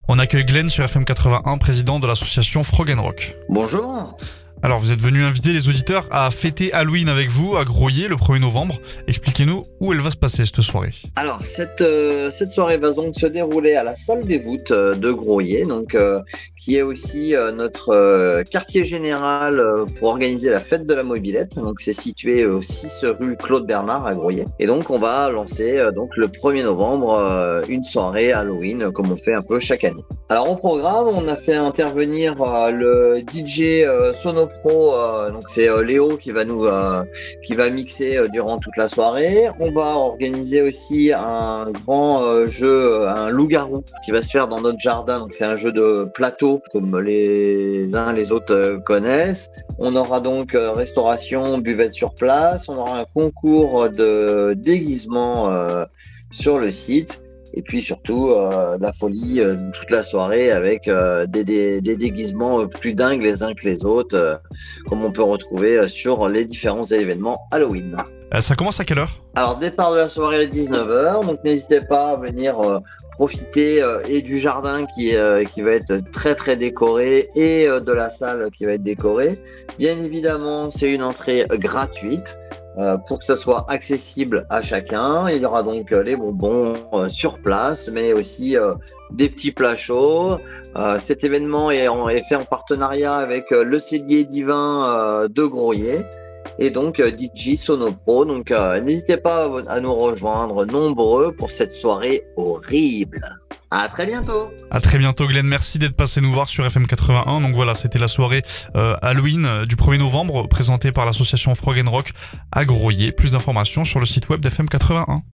LA VIE LOCALE DU TARN